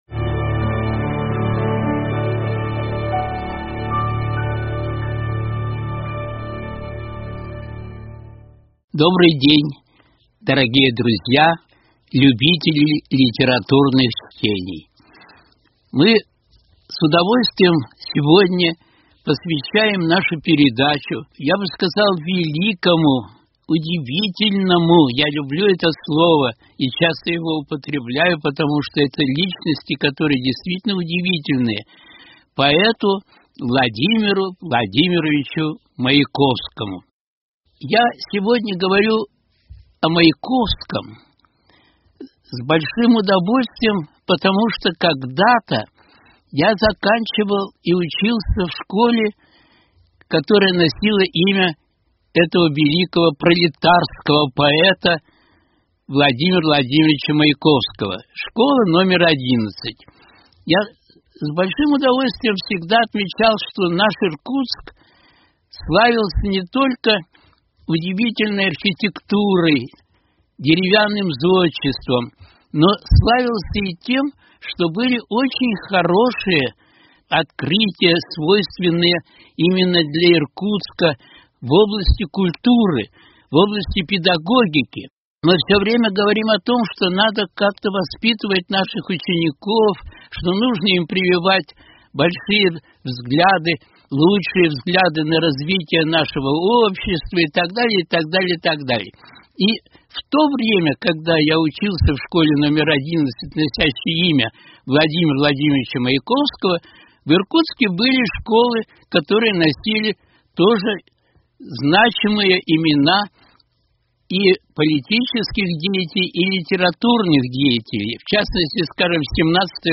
Литературные чтения: Владимир Маяковский 27.08.2021
читает в эфире произведения классиков. В этом выпуске – Владимира Маяковского.